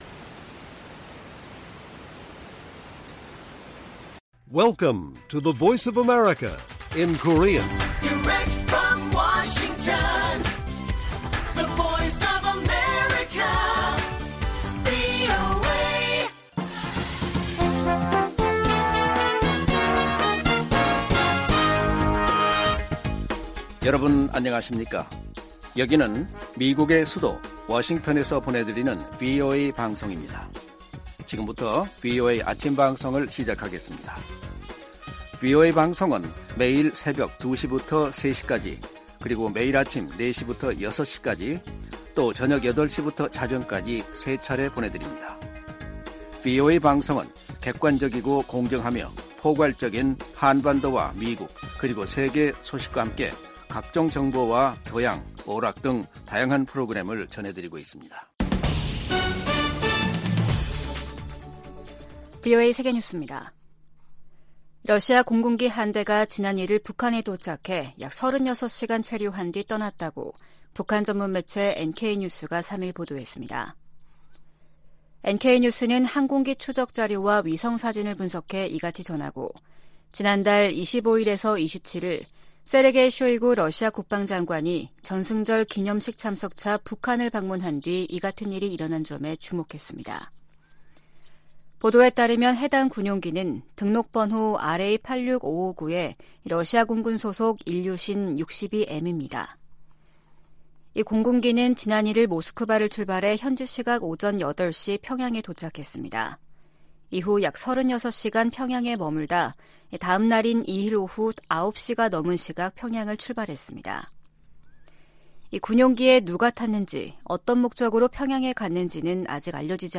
세계 뉴스와 함께 미국의 모든 것을 소개하는 '생방송 여기는 워싱턴입니다', 2023년 8월 4일 아침 방송입니다. '지구촌 오늘'에서는 우크라이나 전쟁이 시작된 이후 지금까지 거의 1만1천 명에 달하는 민간인이 사망했다고 우크라이나 검찰이 밝힌 소식 전해드리고, '아메리카 나우'에서는 지난 2020년 미국 대통령 선거 결과의 전복을 시도한 혐의로 기소된 도널드 트럼프 전 대통령이 워싱턴 DC 연방 지방법원에 출두하는 소식 살펴보겠습니다.